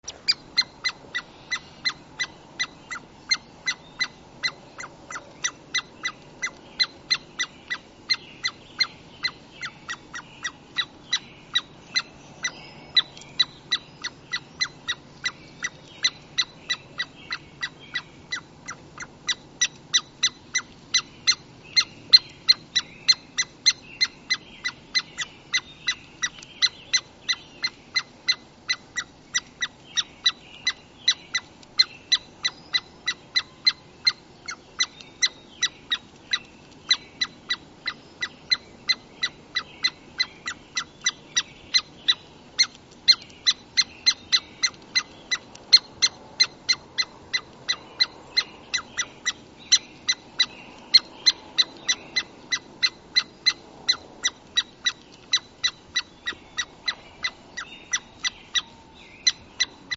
Squirrel-Sound.mp3